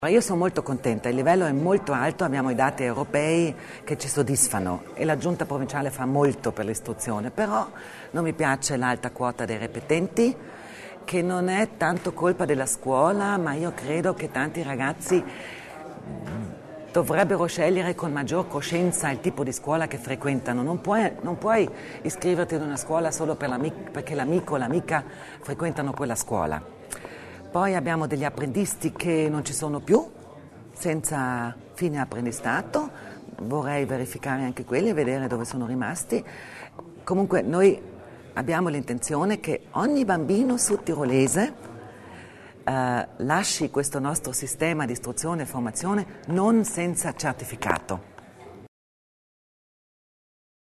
Si è svolta questa mattina a Palazzo Widmann la conferenza stampa, per la prima volta congiunta, degli assessori provinciali alla scuola per un bilancio dell’anno scolastico appena concluso.